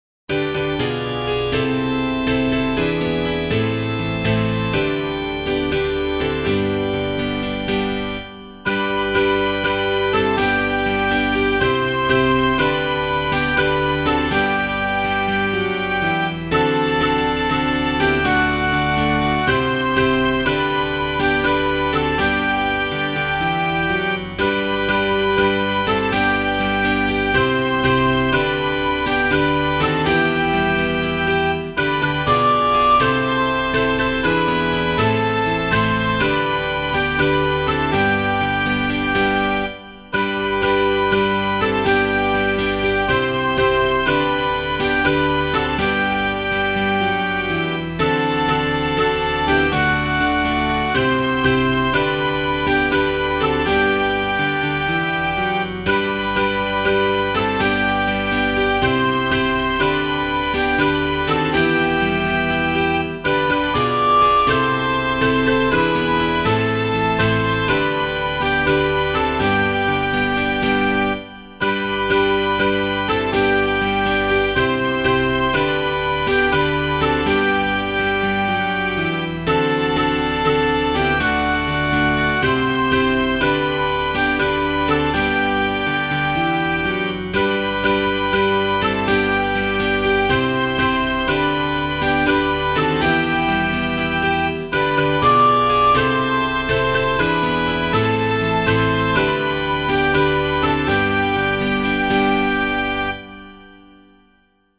(05:55) Opening Hymn .